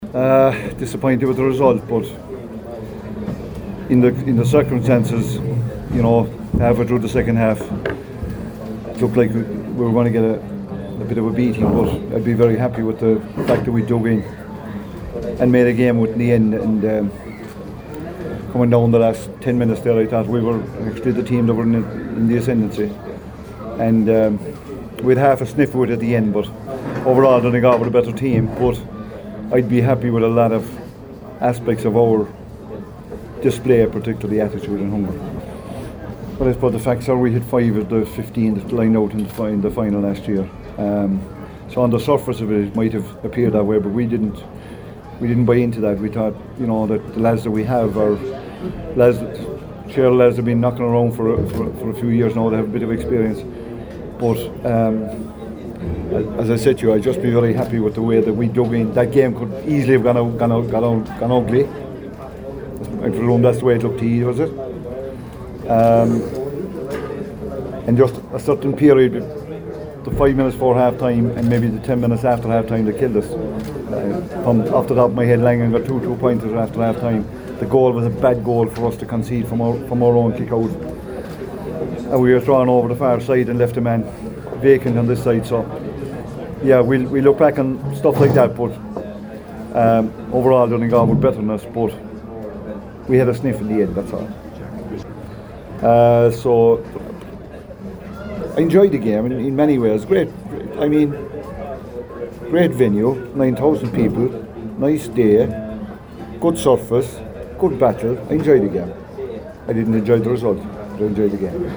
Kerry boss Jack O’Connor told the media after the game that he enjoyed it but not the result…